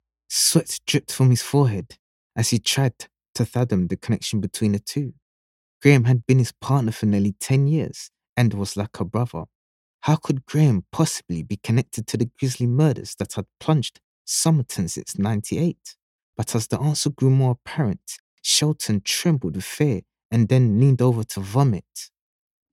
Audio Book Voice Over Narrators
English (Caribbean)
Adult (30-50) | Yng Adult (18-29)